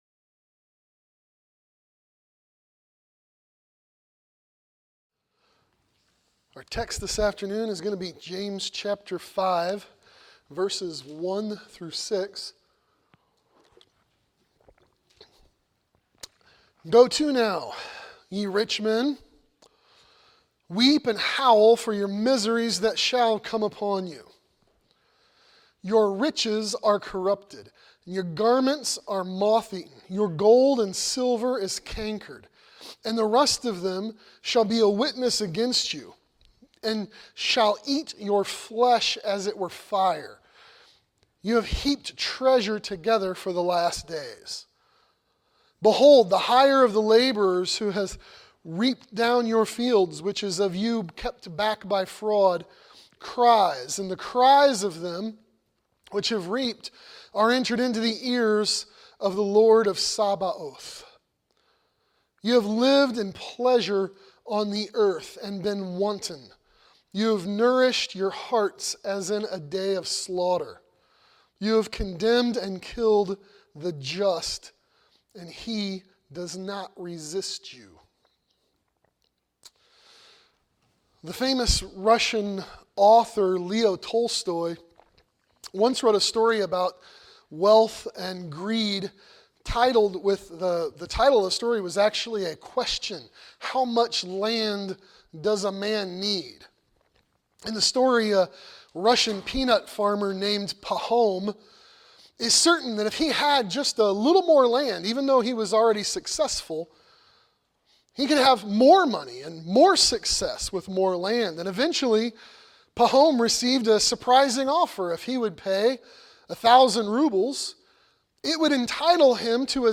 Warning the Wicked Wealthy | SermonAudio Broadcaster is Live View the Live Stream Share this sermon Disabled by adblocker Copy URL Copied!